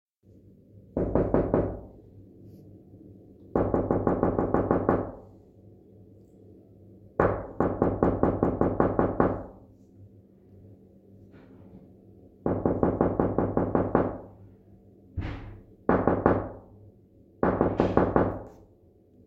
knockknock.mp3